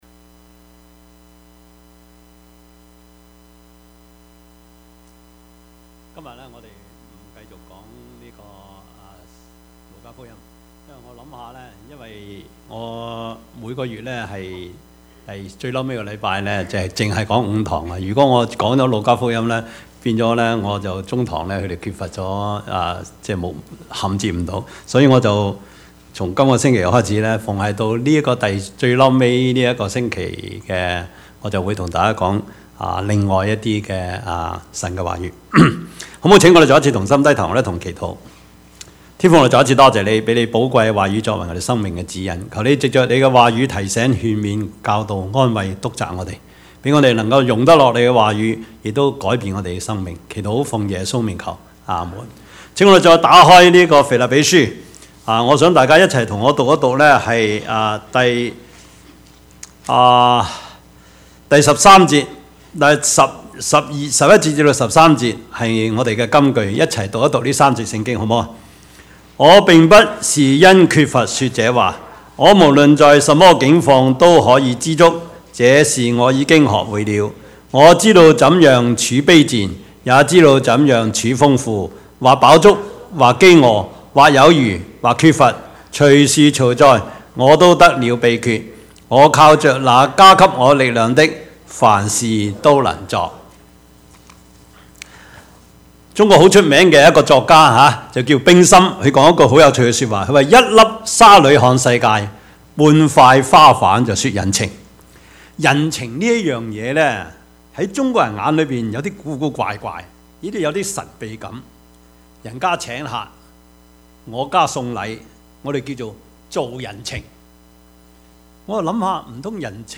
Service Type: 主日崇拜
Topics: 主日證道 « 未知死、焉知生?